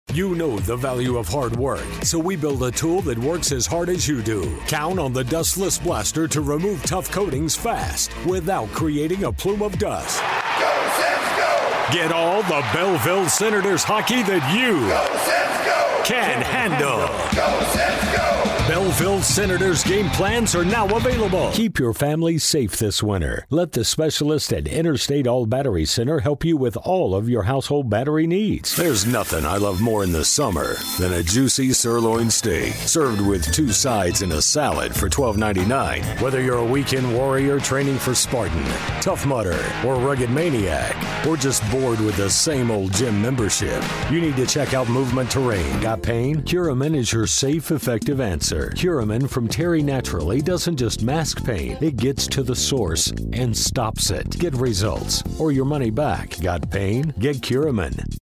Urgent, Conversational, Excited Available Now For Commercial Voiceover, TV and RadioTurnaround: 24 hours